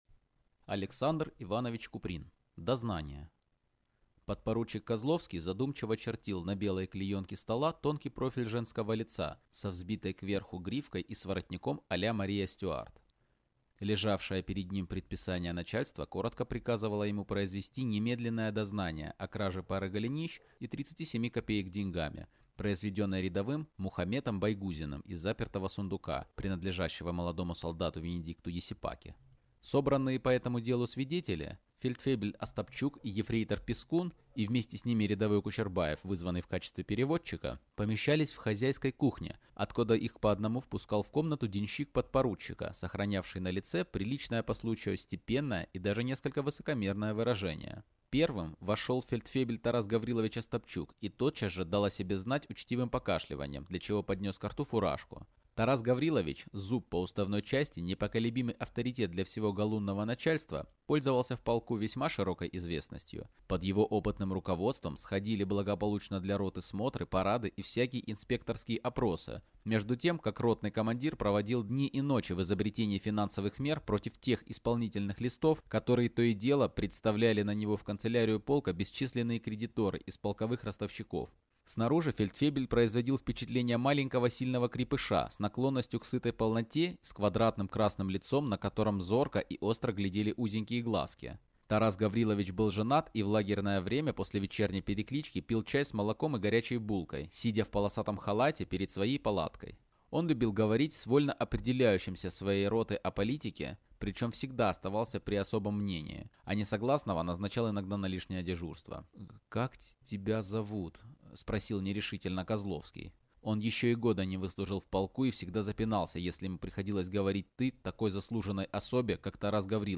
Аудиокнига Дознание | Библиотека аудиокниг